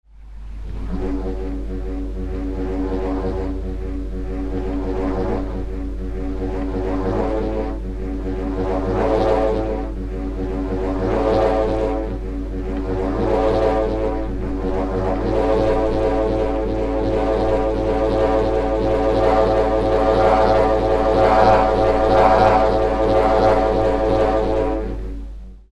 Windwand Bullroarer
Если у австралийского буллрорера звук возникает при раскручивании свистка на веревке, то Windwand раскручивается на штыре, удерживаемом в руке, и звук возникает за счет вибрации резинки натянутой на крестообразное основание. По жужжащему звучанию больше напоминает шум пчелиного роя, нежели рев быка.